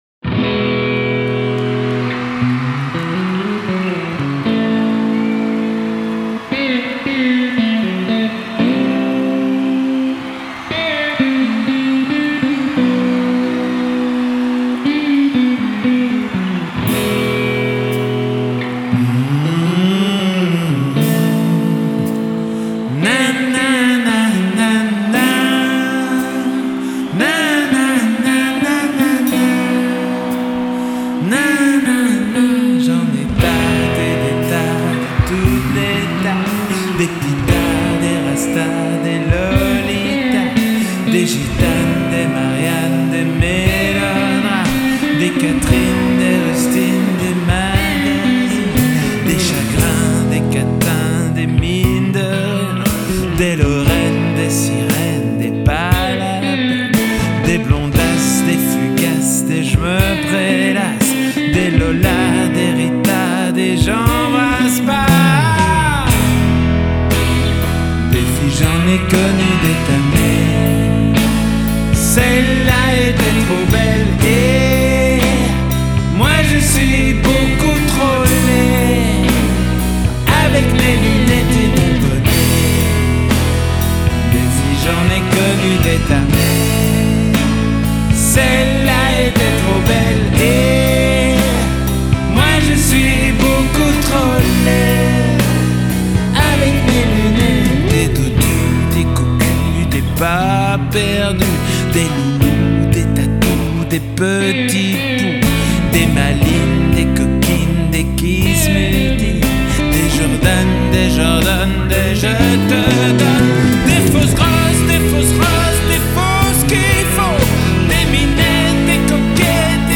Du coup, on croit que tout le morceau va être un "faux live", et en fait, eh bien non! A la fin, la reverb rerentre progressivement. Ça sonne pas comme du studio, la batterie part très en retrait, mais c'est comme ça que j'aime ce morceau :-)